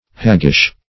Search Result for " haggish" : The Collaborative International Dictionary of English v.0.48: Haggish \Hag"gish\ (-g[i^]sh), a. Like a hag; ugly; wrinkled.